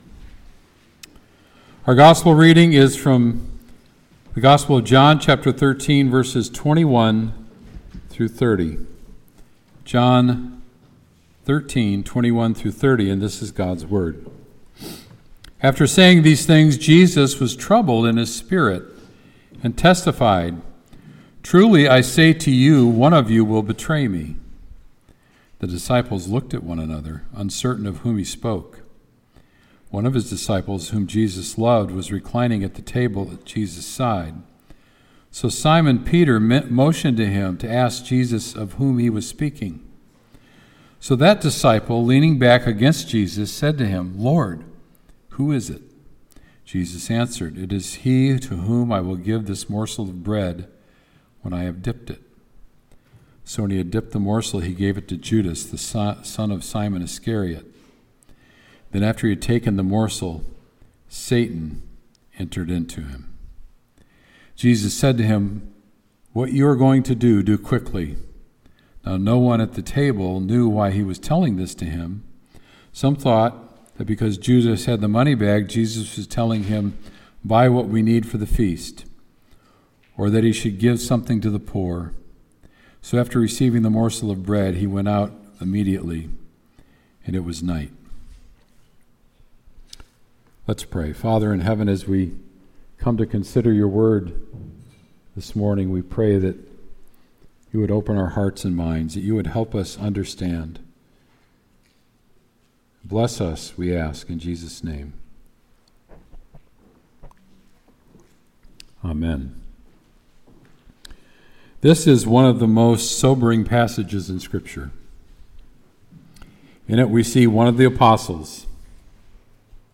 Sermon “Judas Betrays Jesus”